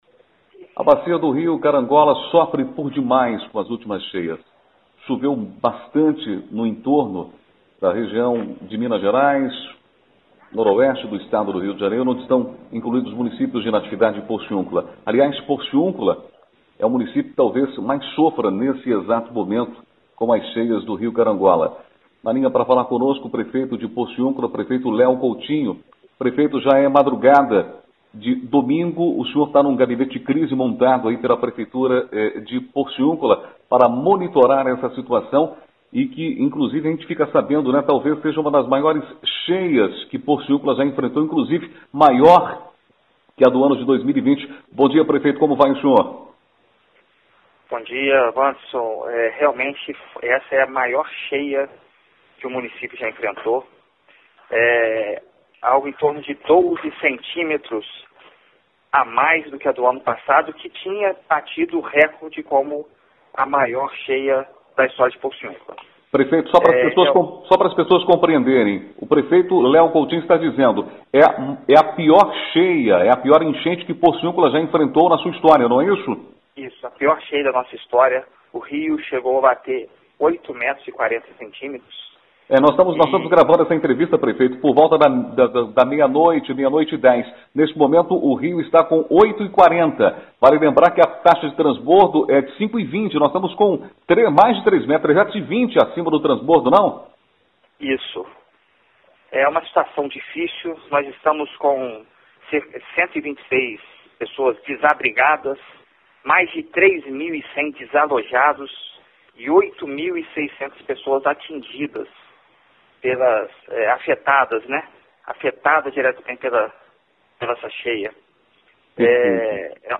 Na madrugada deste domingo (21), o prefeito de Porciúncula concedeu entrevista à Rádio Natividade, na qual afirmou que a cidade passa pela pior enchente de toda a sua história.
21 fevereiro, 2021 DESTAQUE, ENTREVISTAS, REGIÃO